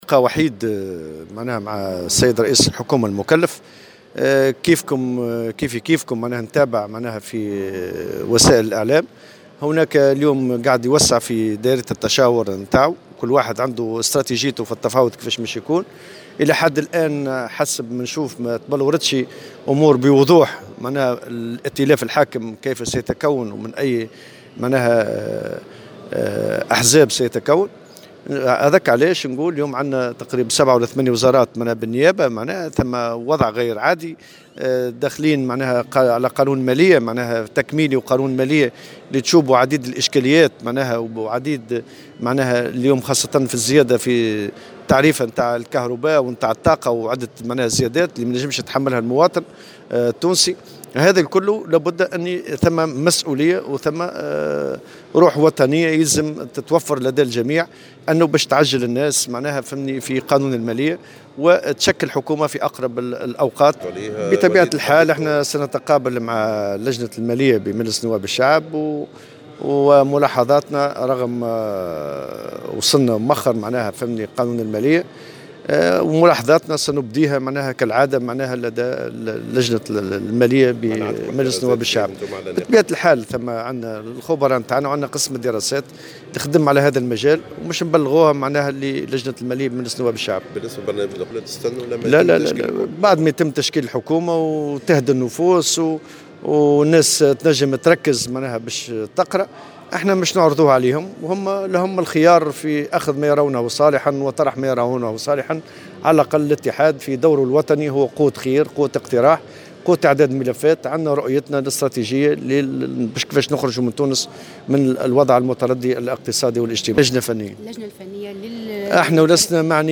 وتابع في تصريح صحفي على هامش اجتماع الجامعة العامة للطفولة، أن رئيس الحكومة المكلف الحبيب الجملي بصدد توسيع دائرة التشاور لتشكيل حكومته، مشيرا إلى لقاء "وحيد" جمعه برئيس الحكومة المكلف. وفي سياق متصل، اعتبر الوضع غير عادي بالنظر إلى تزامنه مع مناقشة ميزانية الدولة وتسيير عدة وزارات بالنيابة، مشدّدا على أهمية تشكيل حكومة في أقرب وقت.